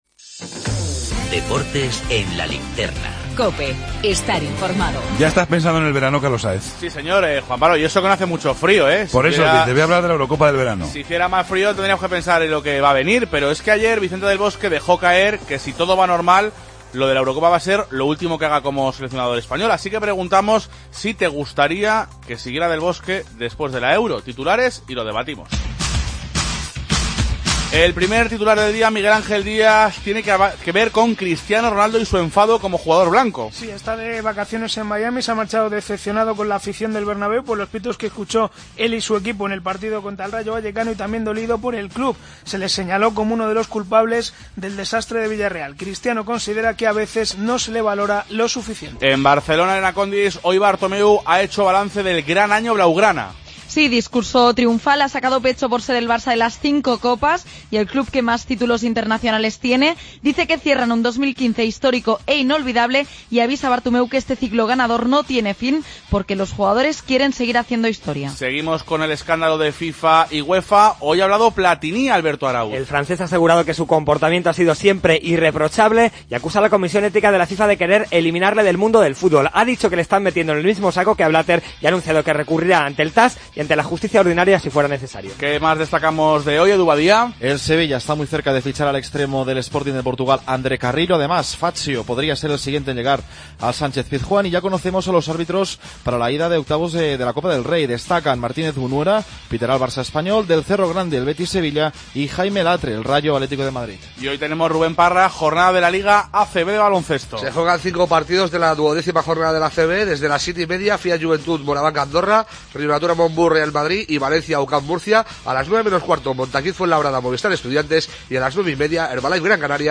El debate